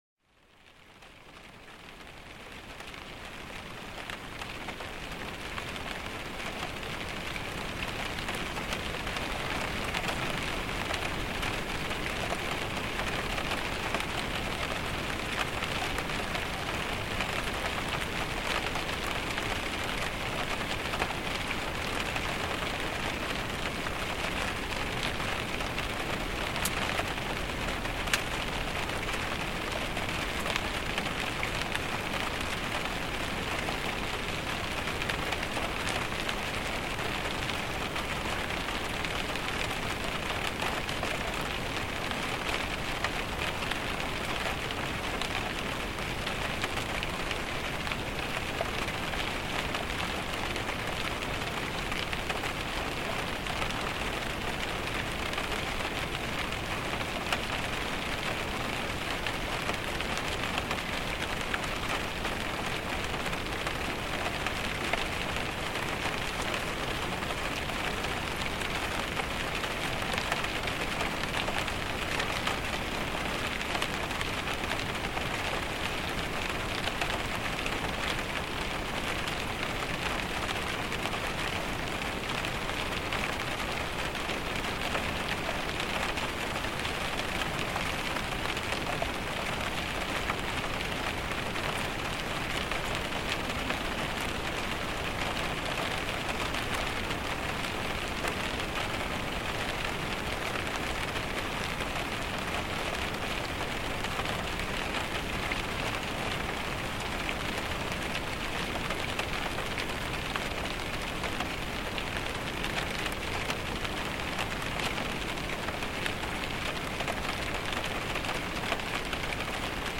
Lluvia sobre Ventana de Auto para un Descanso Pacífico